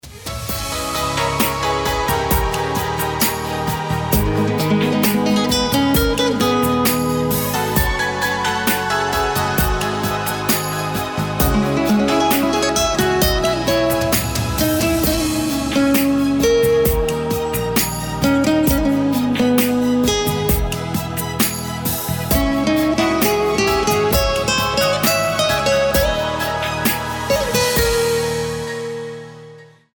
• Качество: 320, Stereo
гитара
душевные
без слов
красивая мелодия
пианино
медленные